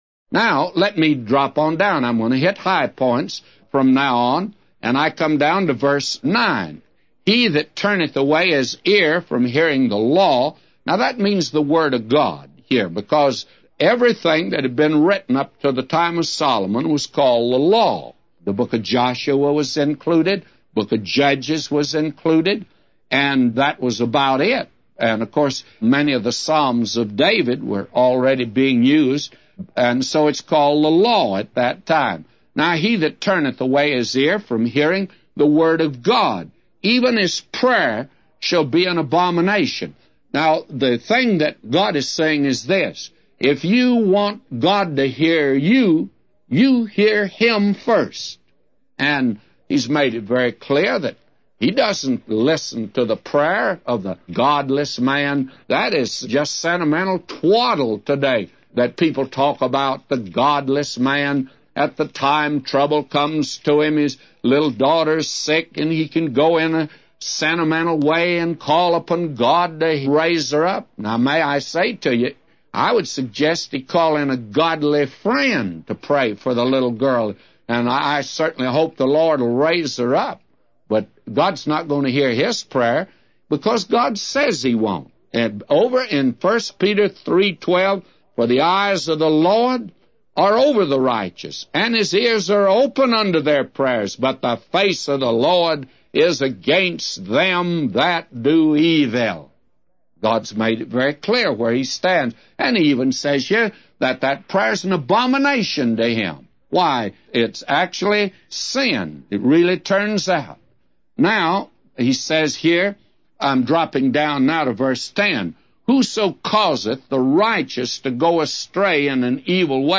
A Commentary By J Vernon MCgee For Proverbs 28:9-999